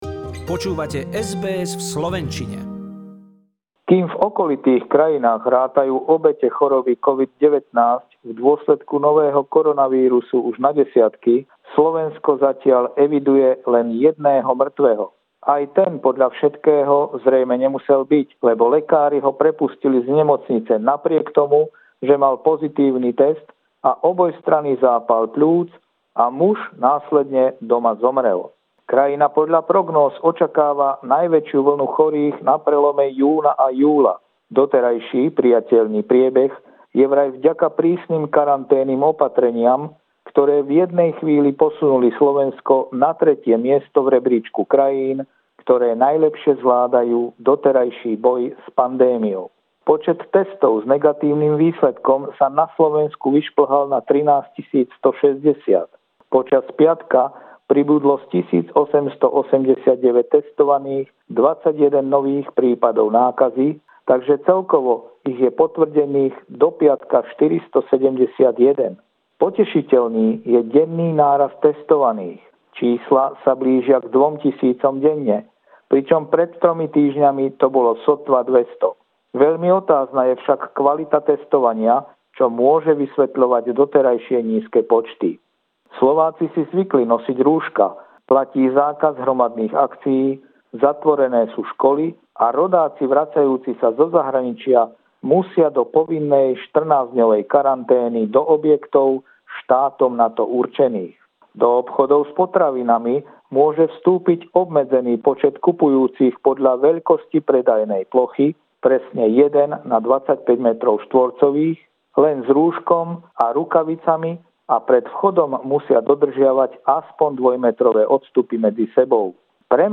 Regular stringer report